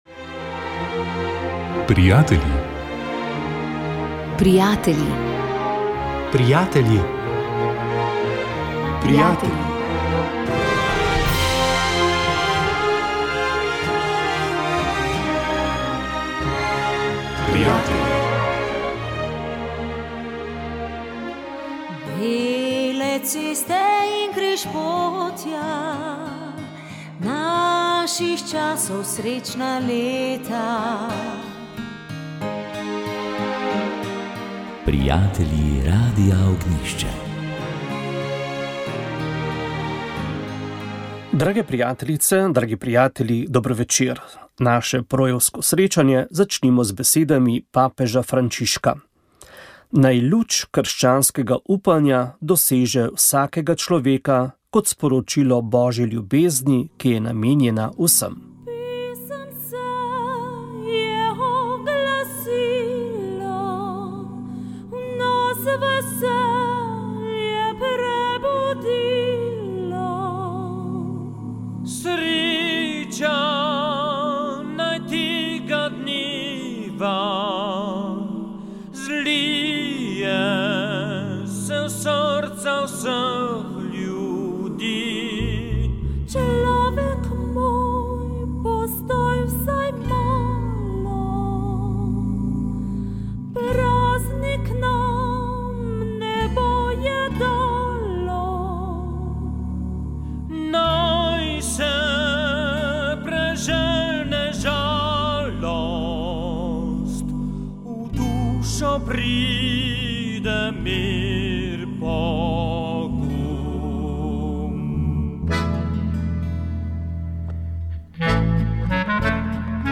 Tudi v letošnjem letu oddaja vsak zadnji ponedeljek v mesecu poteka živo, glavno besedo pa ima glasba - polke in valčki po željah poslušalk in poslušalcev.